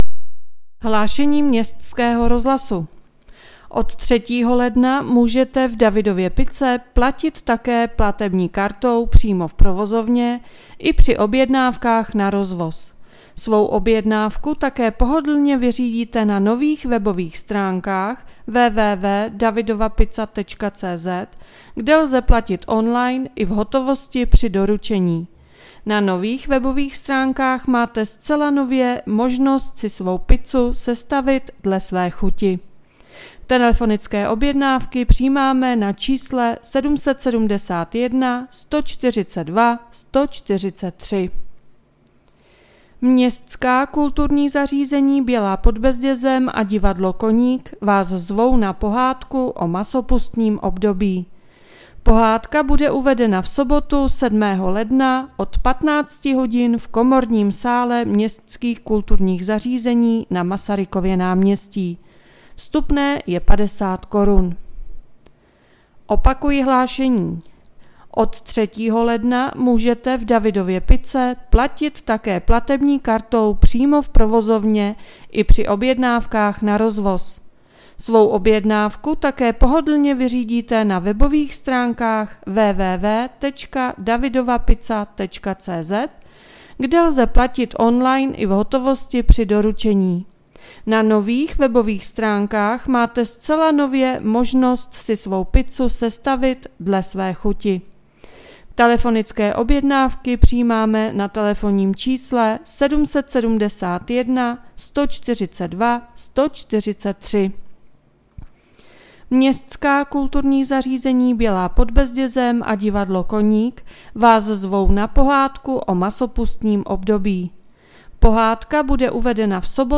Hlášení městského rozhlasu 4.1.2023